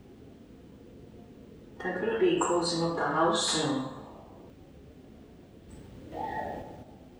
But it was sharp, loud and to this day gives us a bit of a chuckle.